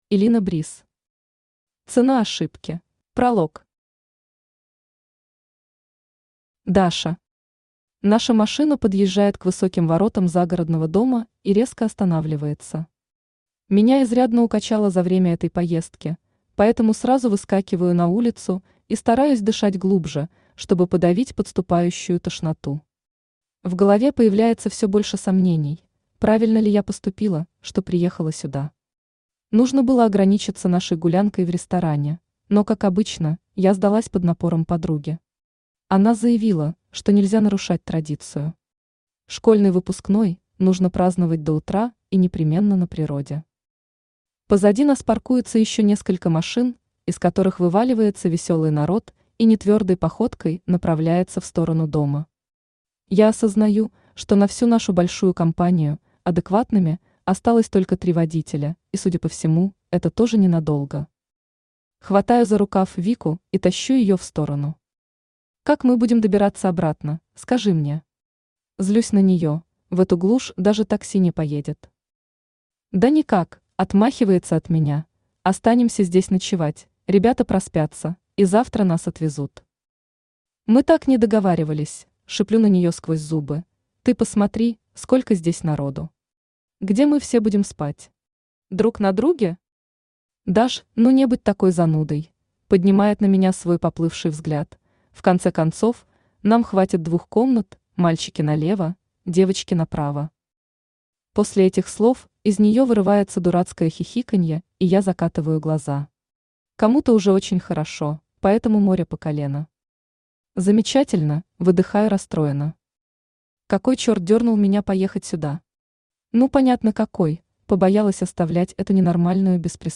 Аудиокнига Цена ошибки | Библиотека аудиокниг
Aудиокнига Цена ошибки Автор Элина Бриз Читает аудиокнигу Авточтец ЛитРес.